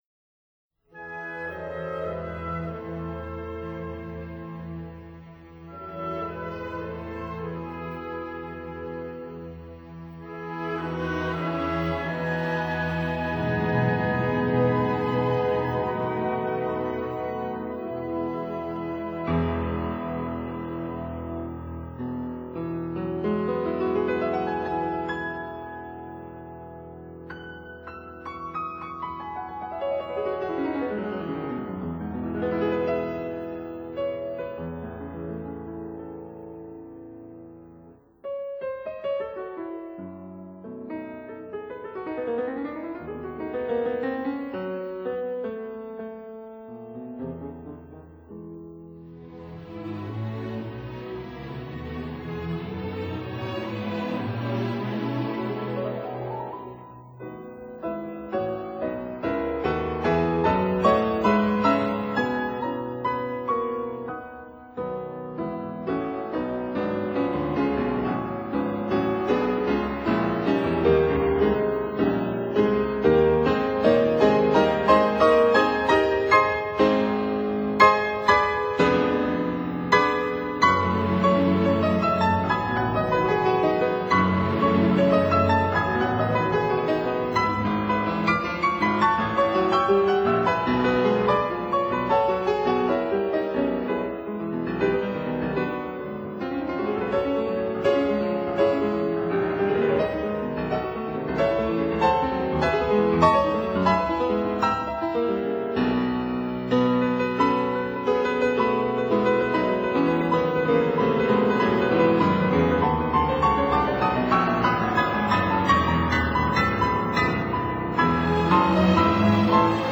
suite for orchestra